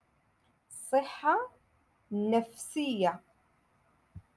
Moroccan Dialect-Rotation Six-Lesson Twenty Two